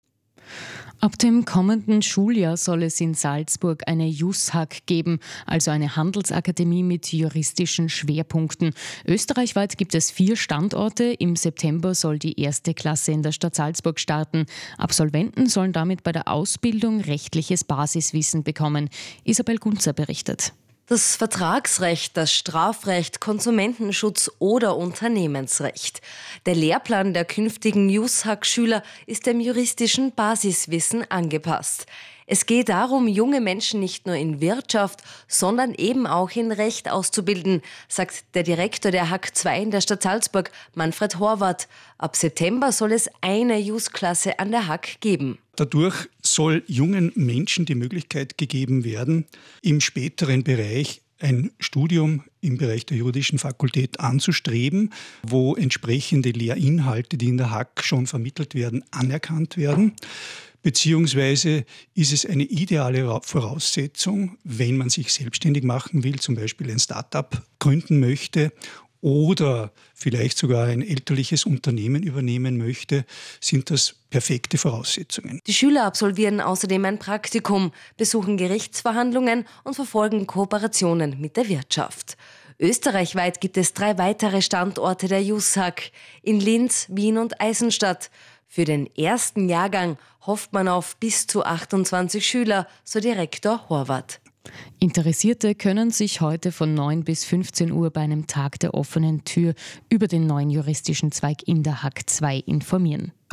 Radiobericht über die Einführung der JusHAK